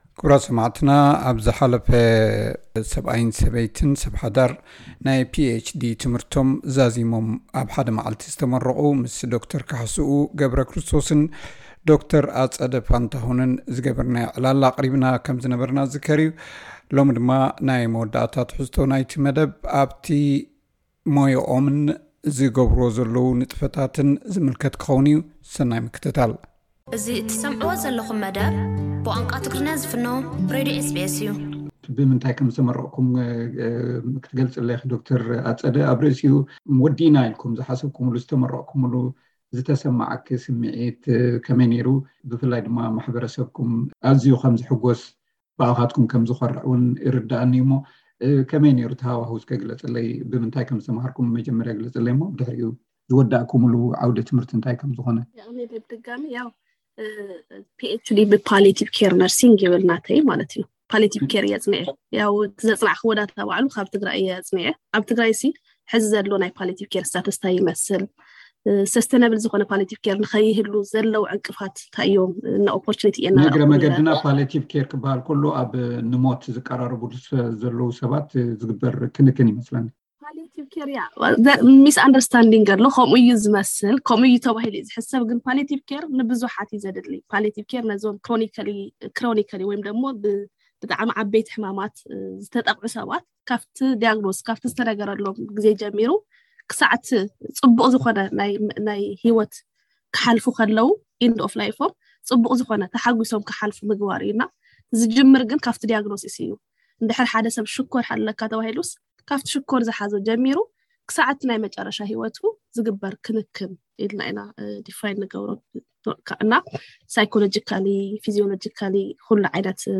ዕላል ምስ ኣብ ሓደ መዓልቲ ብናይ ዶክትሬት ማዕርግ ዝተመረቑ ሰብኣይን ሰበይቲን።